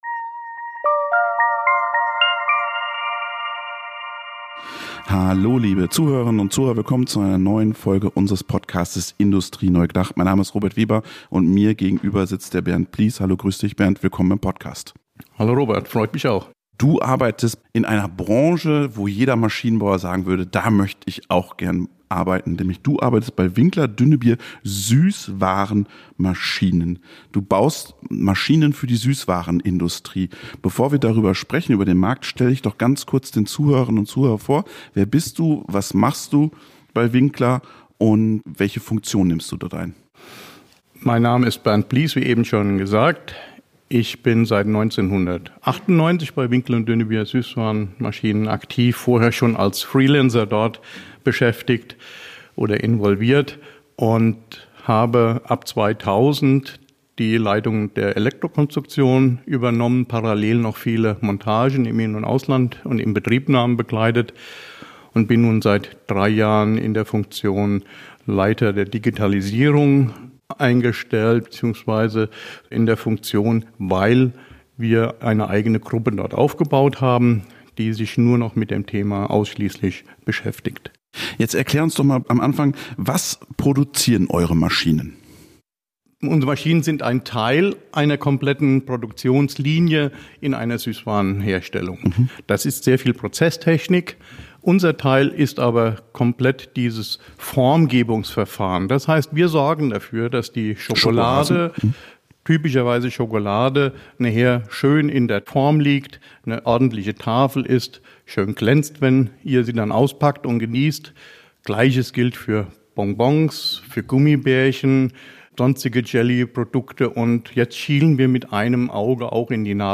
Im Gespräch geht es um den digitalen Wandel und die Herausforderung, das Handwerk der alten Meister in eine zunehmend automatisierte Zukunft zu führen.